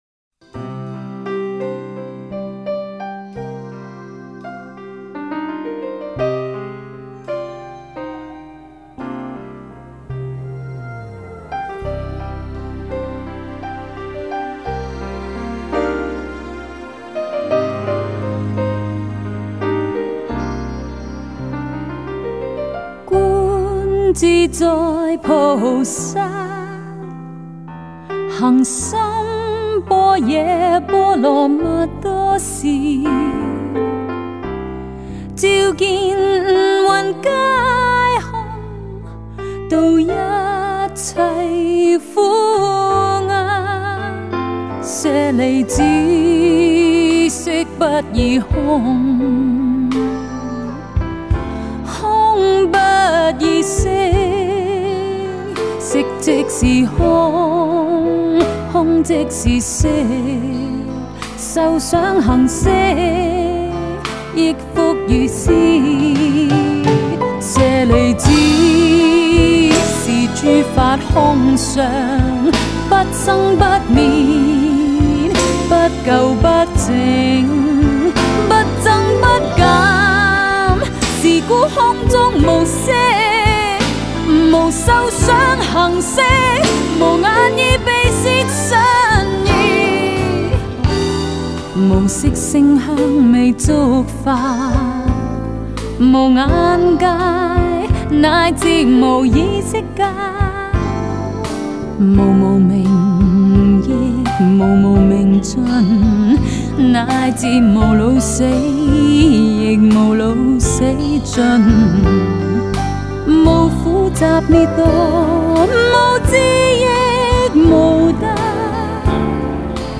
佛曲音樂 > 經典唱誦